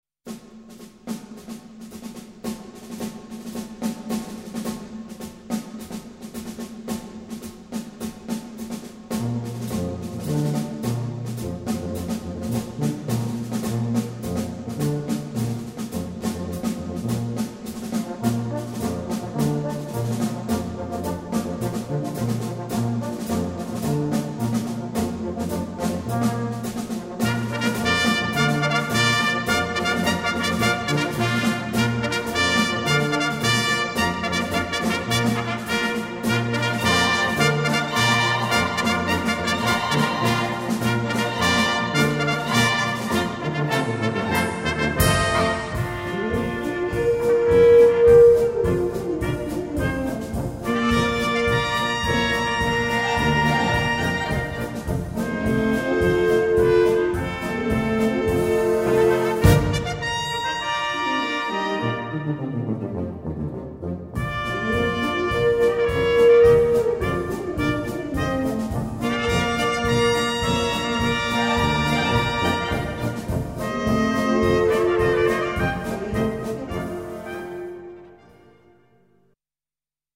Gattung: Polka
A4 Besetzung: Blasorchester Zu hören auf